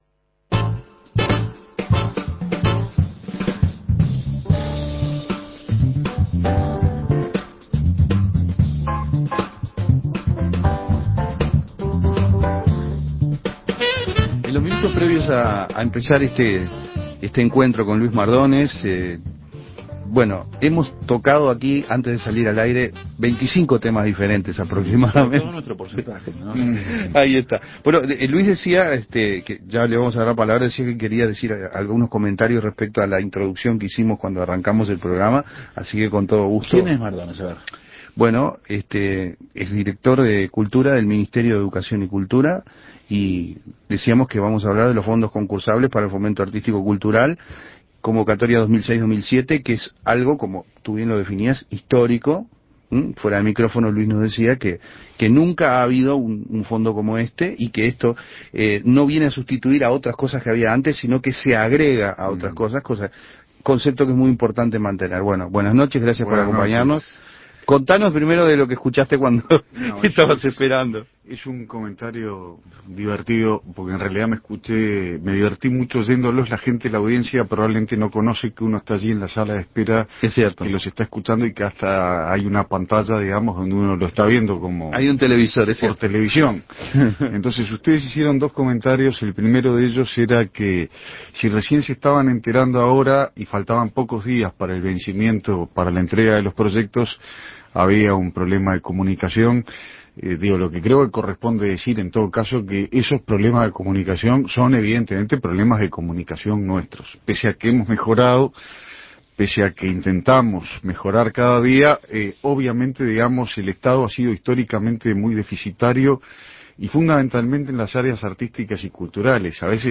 Luis Mardones, director de Cultura del Ministerio de Educación y Cultura, habló de un llamado que por primera vez se realiza en Uruguay: los Fondos Concursables para el Fomento Artístico Cultural del MEC. Esta convocatoria integra a las artes visuales, la danza, el teatro, las letras, el relato gráfico, la documentación de historia, la música, la investigación, la formación, la extensión, entre otras.